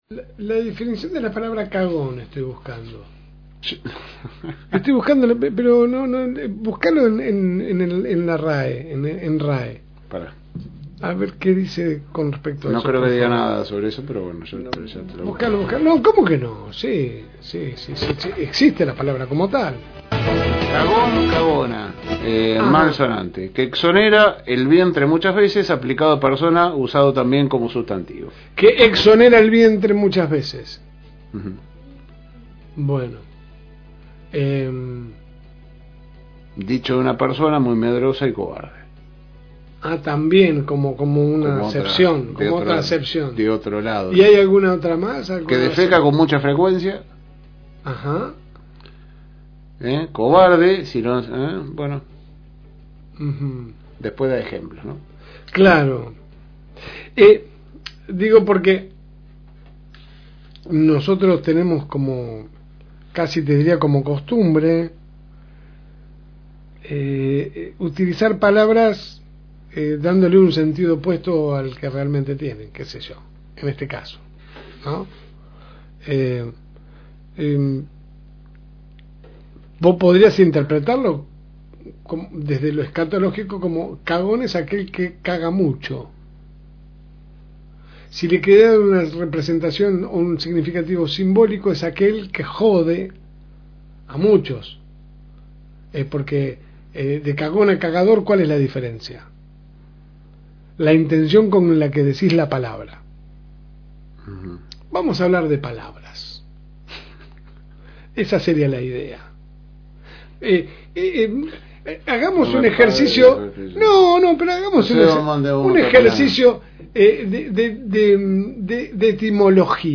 AUDIO – Editorial de La Segunda Mañana – FM Reencuentro
La Segunda Mañana sale de lunes a viernes de 10 a 12 HS por el aire de la Fm Reencuentro 102.9